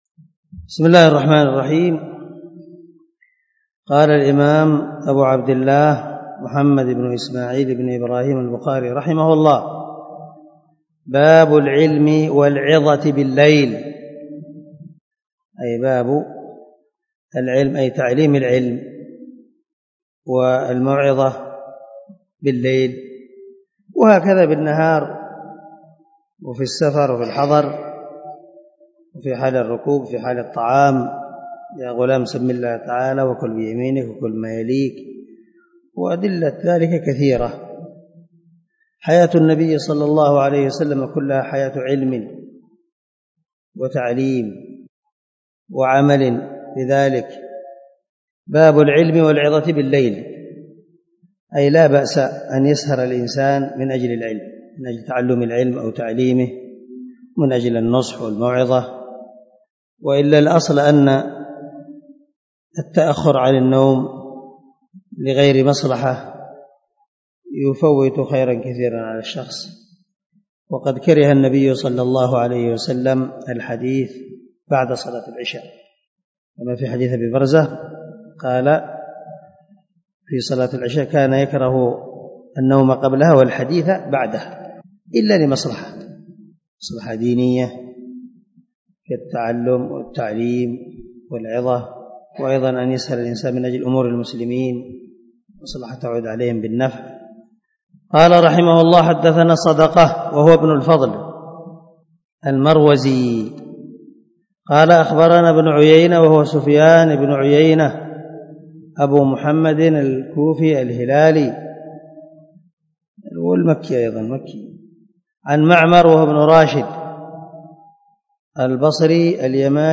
108الدرس 53 من شرح كتاب العلم حديث رقم ( 115 ) من صحيح البخاري